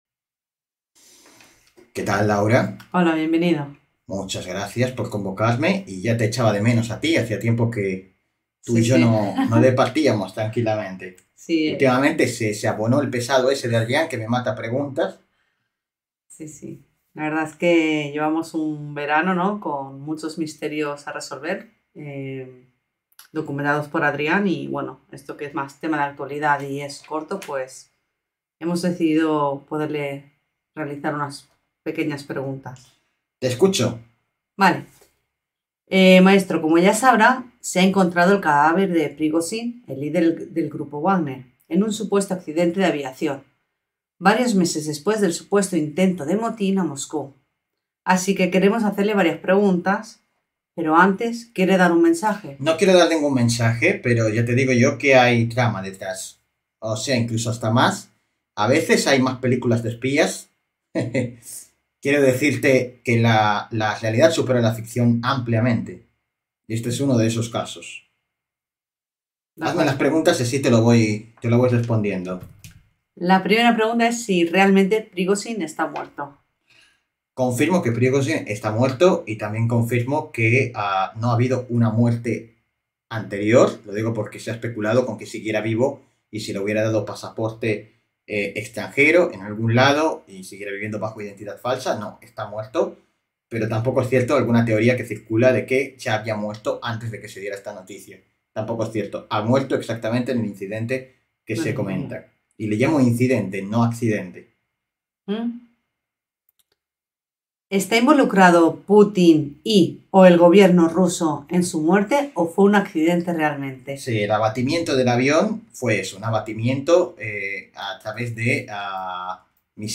como médium canalizador
como interlocutora